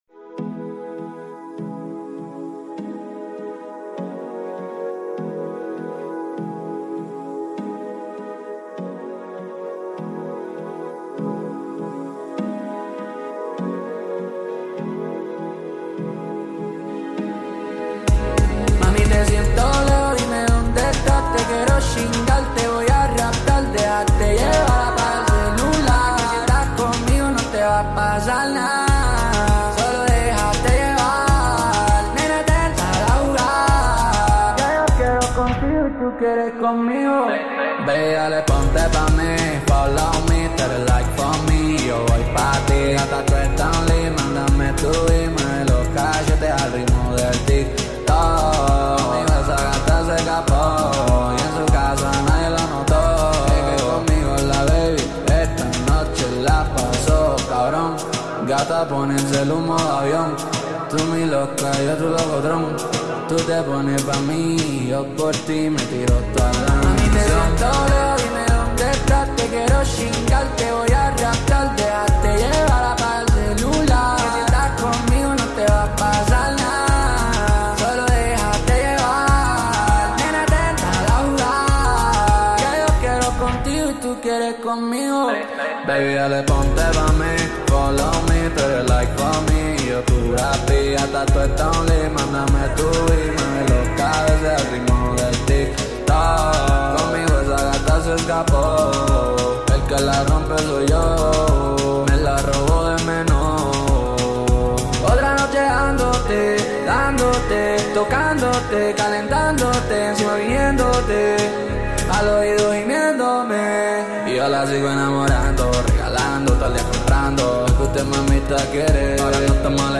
یک آهنگ رگتون شاد و پرانرژی